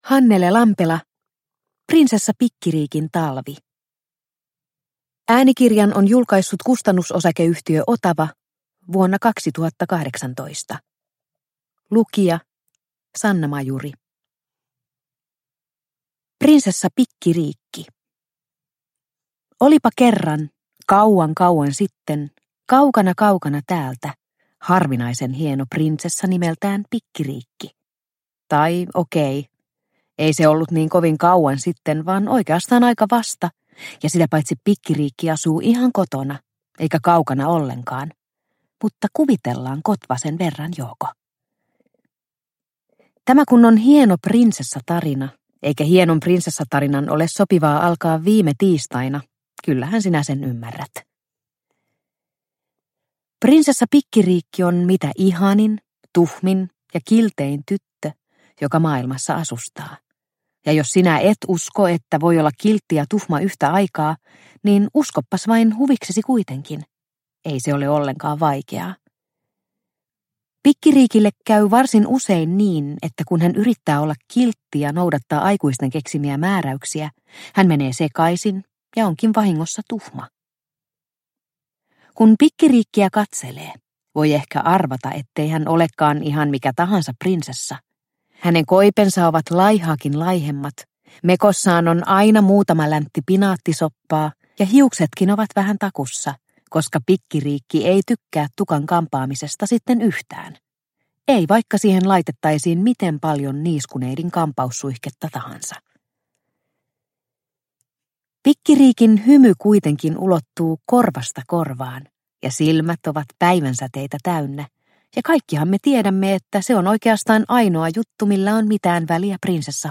Prinsessa Pikkiriikin talvi – Ljudbok – Laddas ner